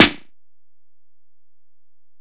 Snare (56).wav